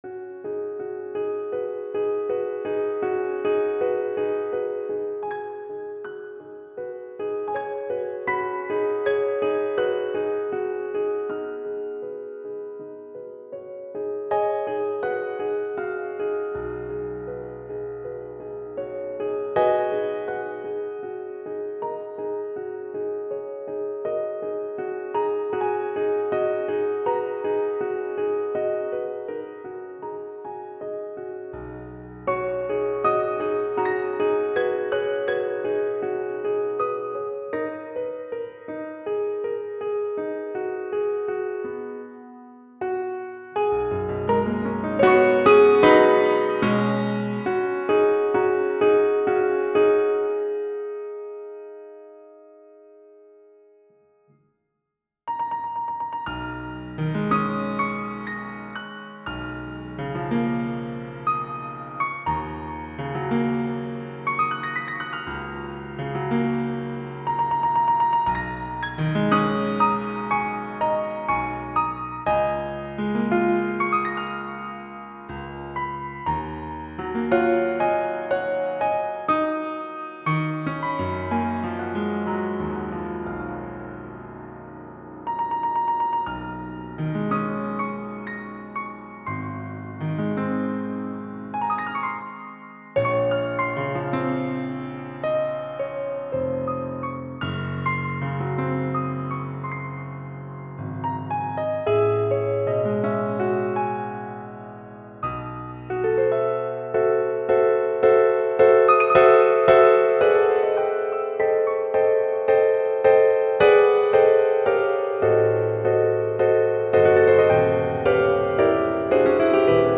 Minerals, for Solo Piano